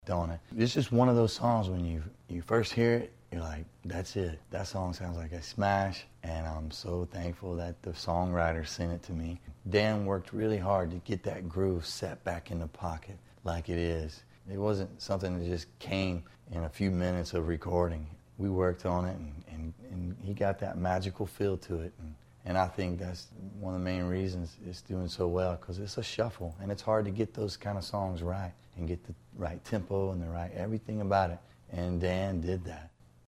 BILLY CURRINGTON TALKS ABOUT RECORDING HIS NO. 1 SINGLE, “DON’T IT.”